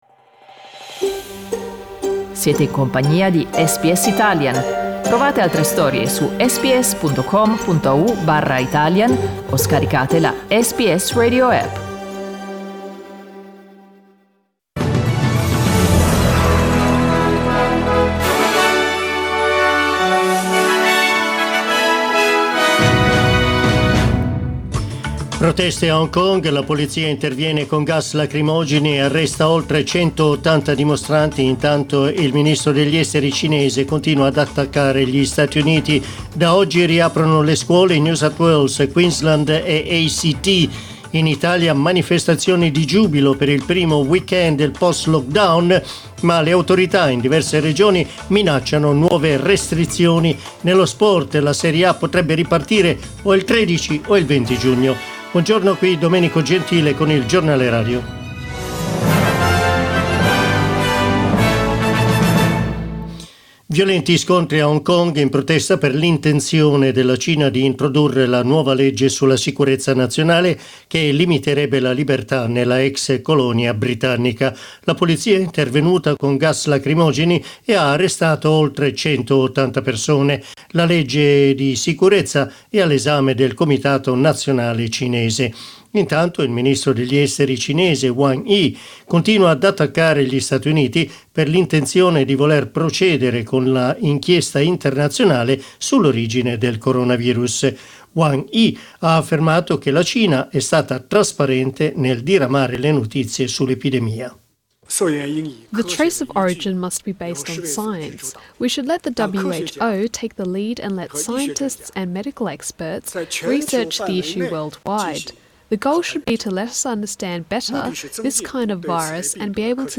Our news bulletin (in Italian).